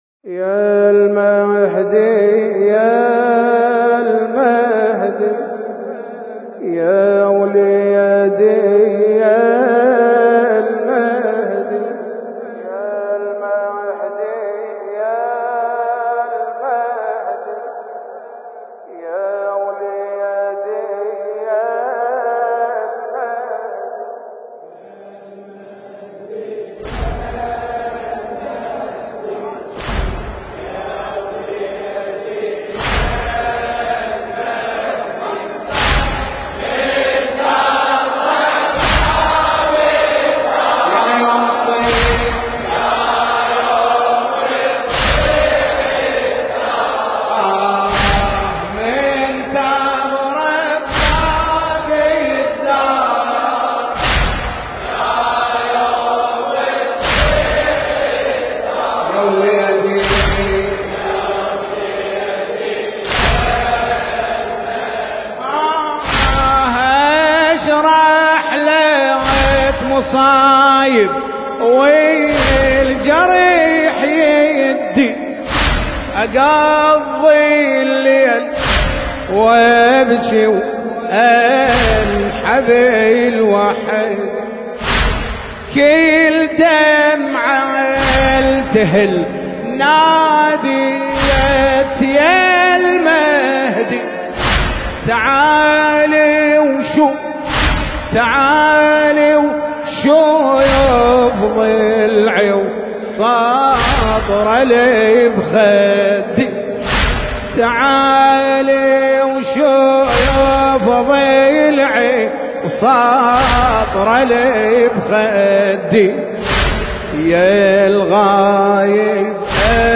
المكان: هيئة شباب علي الأصغر (عليه السلام) – كربلاء المقدسة